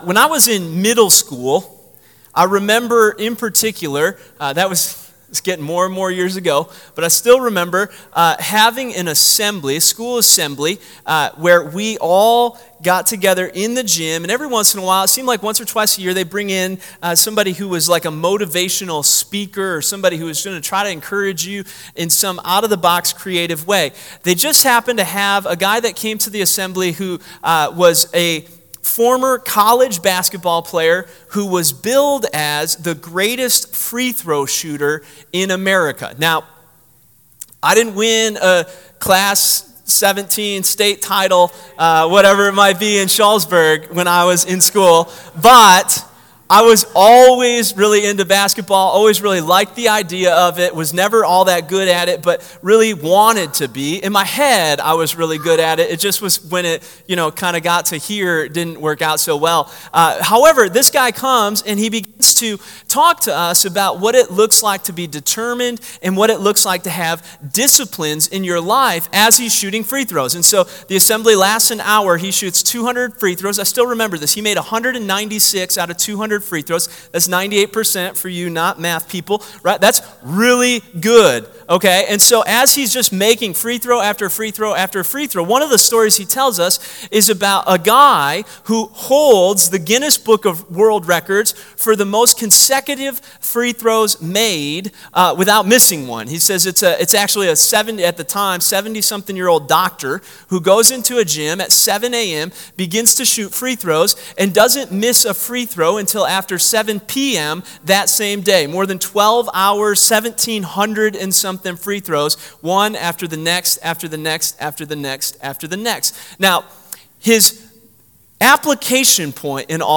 Bible Text: Ezra 7 | Preacher